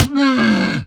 sounds / mob / camel / death1.ogg
death1.ogg